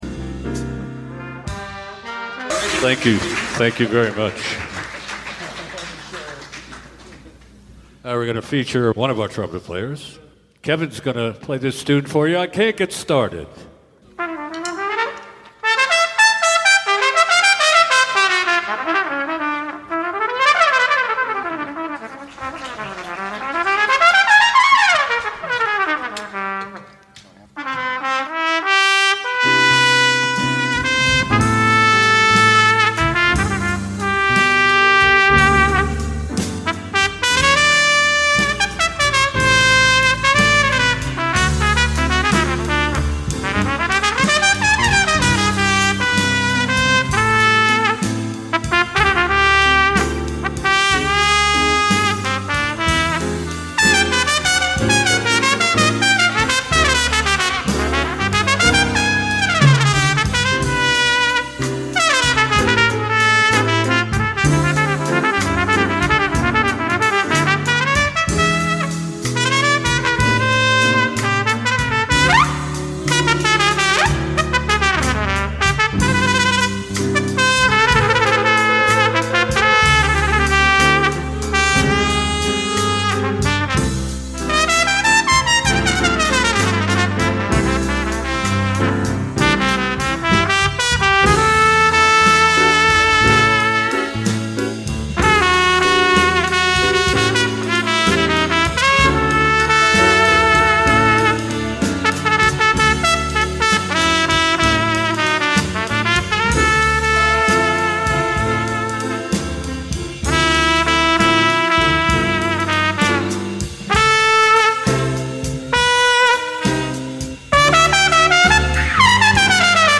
Big Band
live performance Sarasota Jazz Club
oceans_2_tunes_jazz_at_two.mp3